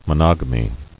[mo·nog·a·my]